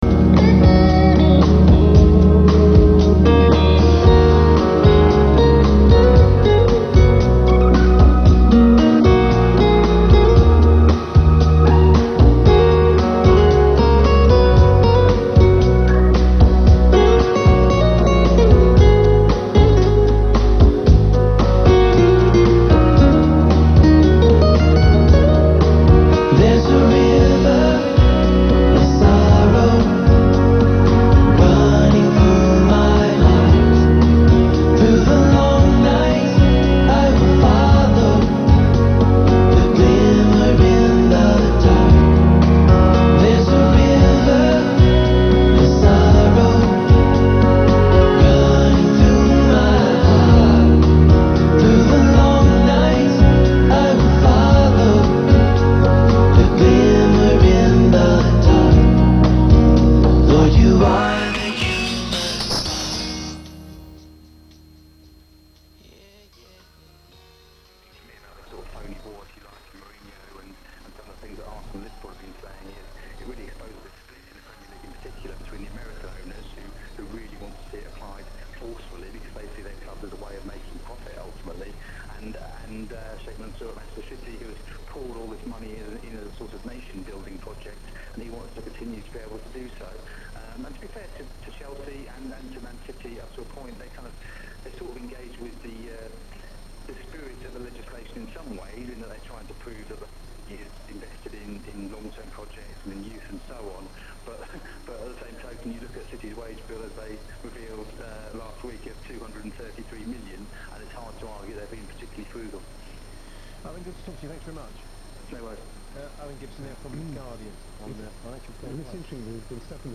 As experiment, I made with the help of the diode detector an audio recording of my detector unit1 .
There is no antenna connected to detector unit 1, the coil in the unit works as a small loop antenna, and receives the radio signal.
Therefore I now record the audio first with a Minidisc recorder, and later transfer this to my PC.
Two stations are to be heard in the audio recording.
The first minute you can hear Groot Nieuws Radio.
Then I tuned to the station Talk Sport (it took 15 seconds before accurately was tuned).
The reception of the second station is much weaker then the first.
This recording demonstrates how the sound quality of the diode detector is, at strong and weak input signals.
This recording is made at daytime, when reception of distant stations is weak.